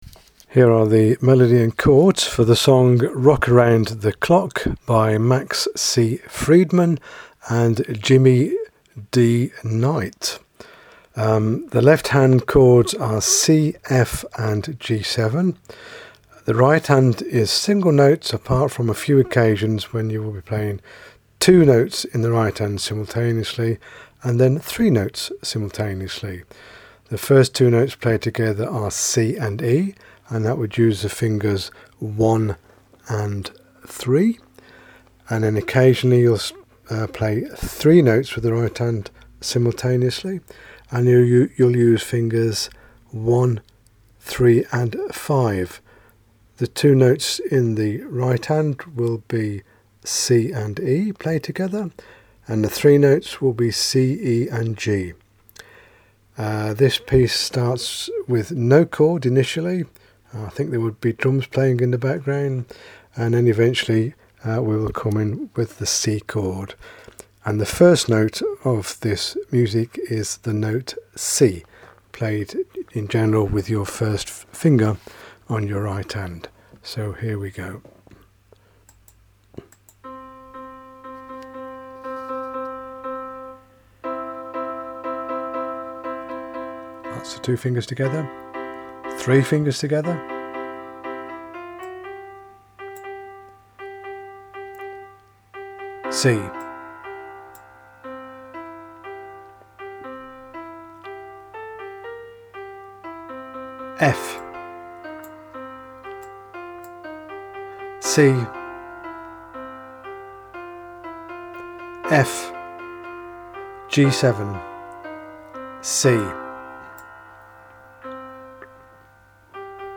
Keyboard Session MP3 Files
including chords